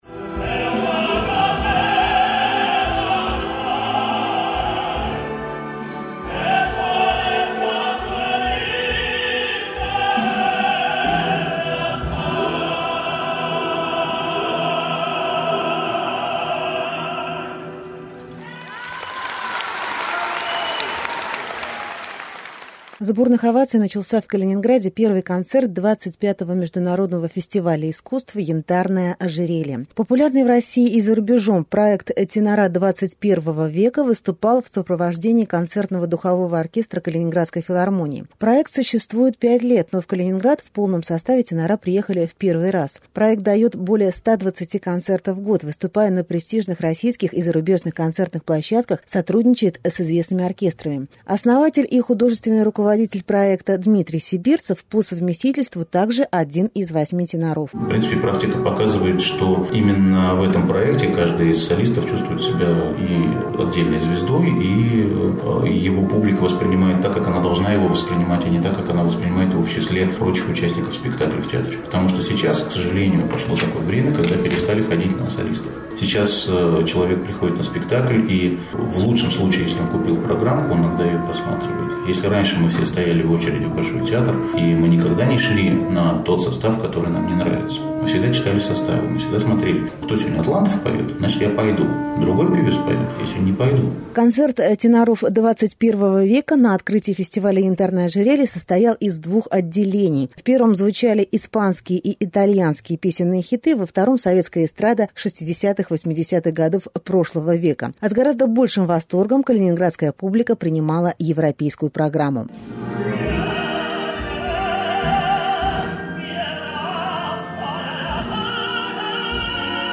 Тенора 21-го века поют в Калининграде